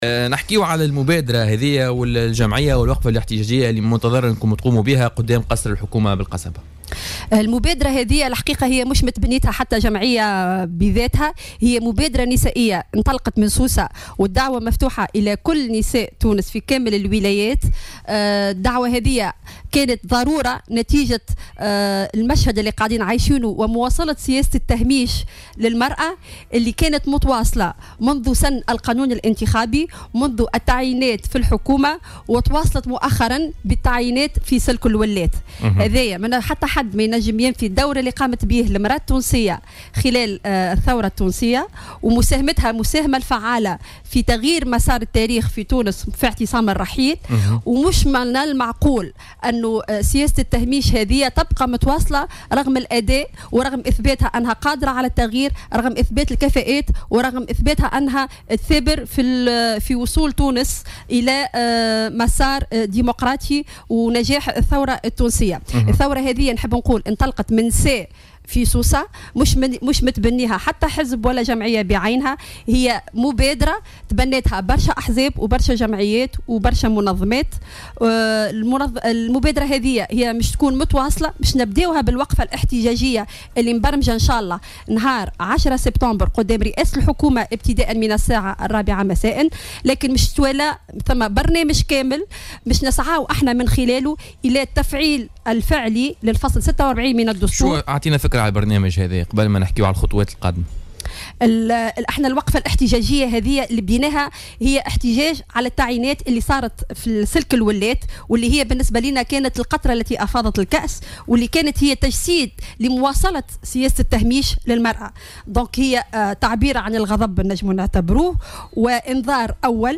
وأوضحت في مداخلة لها في برنامج "بوليتيكا" أن هذه البادرة تأتي على خلفية تواصل سياسة تهميش المرأة وإقصائها من الحياة السياسية ومواقع القرار. وأضافت أنه من المنتظر تنظيم وقفة احتجاجية أمام قصر الحكومة بالقصبة يوم 10 سبتمبر الجاري للتنديد بإقصاء العنصر النسائي في التعيينات الأخيرة للولاة وللمطالبة بتفعيل مبدأ التناصف.